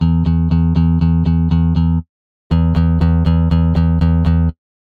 繝斐お繧ｾ繝斐ャ繧ｯ繧｢繝繝励ｒ譛峨ｊ縺ｨ辟｡縺励↓縺励◆髻ｳ濶ｲ縺ｧ縺吶よ怙蛻昴ｮ貍泌･上ｯ繝斐お繧ｾ繝斐ャ繧ｯ繧｢繝繝励ｒ菴ｿ逕ｨ縺励※縺縺ｪ縺縺溘ａ縲騾壼ｸｸ縺ｮ繝吶ｼ繧ｹ繧ｵ繧ｦ繝ｳ繝峨↓縺ｪ縺｣縺ｦ縺縺ｾ縺吶
谺｡縺ｮ貍泌･上ｯ繝斐お繧ｾ繝斐ャ繧ｯ繧｢繝繝励ｒ菴ｿ逕ｨ縺励※縺繧九◆繧√√け繝ｪ繧｢縺ｧ郢顔ｴｰ縺ｪ髻ｿ縺阪′蜉繧上ｊ縲√い繧ｳ繝ｼ繧ｹ繝繧｣繝繧ｯ縺ｪ雉ｪ諢溘ｮ縺ゅｋ繧ｵ繧ｦ繝ｳ繝峨↓縺ｪ縺｣縺ｦ縺縺ｾ縺吶